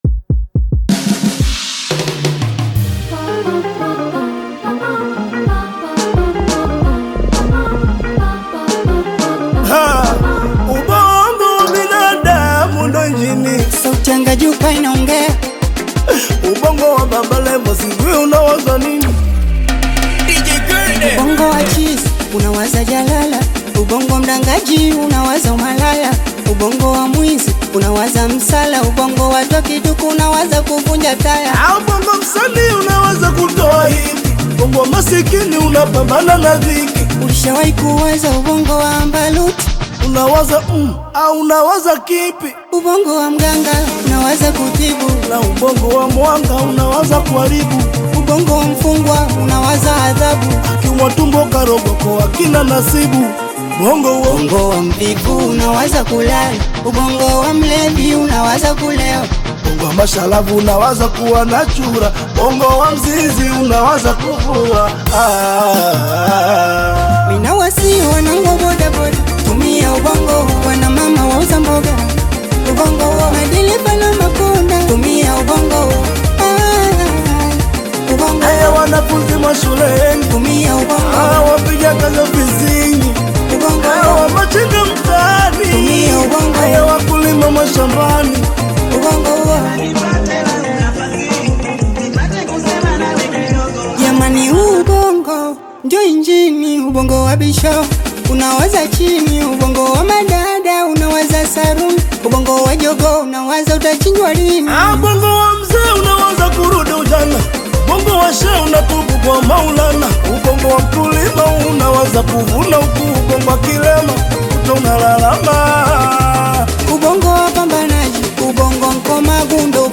Tanzanian bongo flava and singeli
African Music